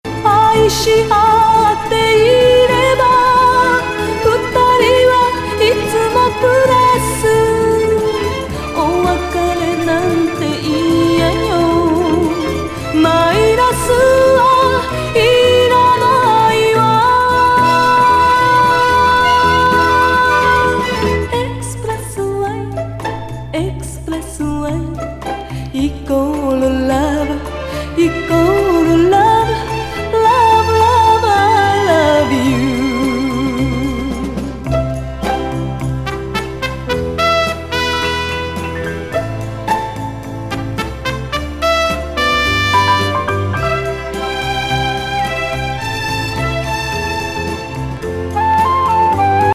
雰囲気抜群フェロモン・ムード歌謡!